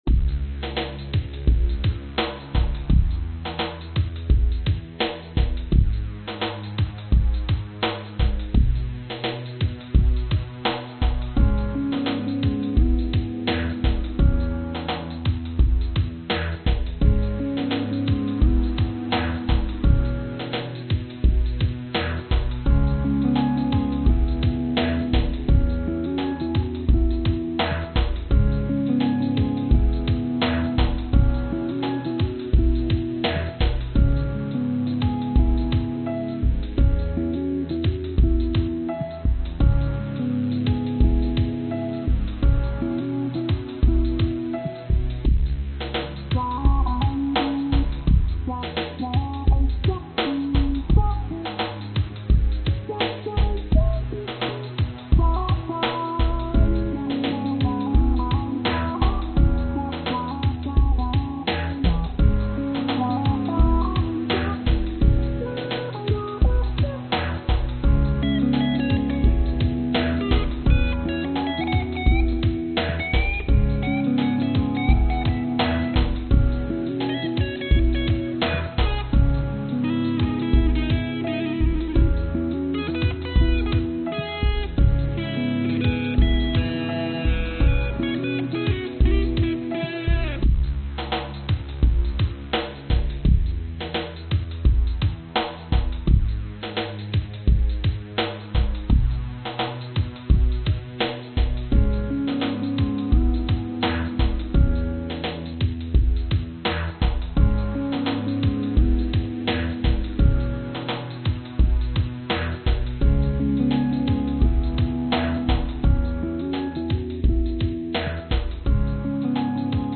描述：GTR和弦在一个MIDI序列上通过VST合成器Rapture播放。
标签： Cminor 吉他 Rapture 软音源 VST
声道立体声